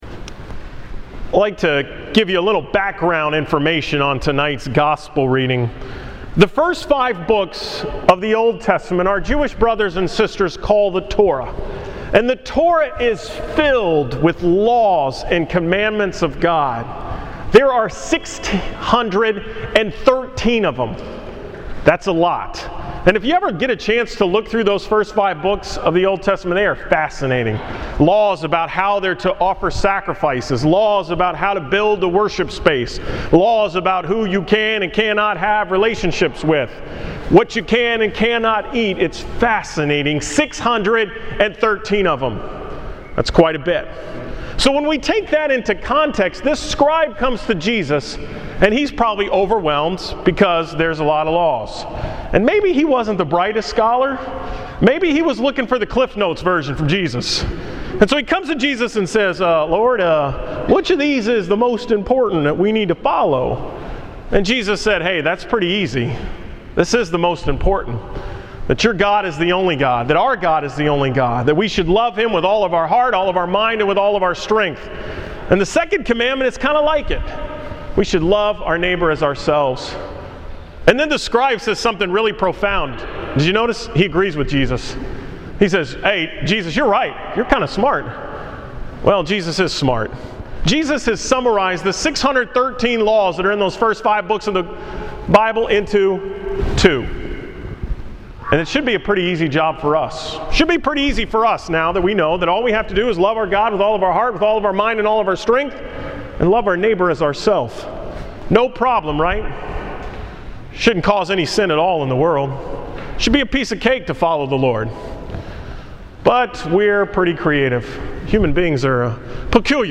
Homily from the weekend of November 3-4.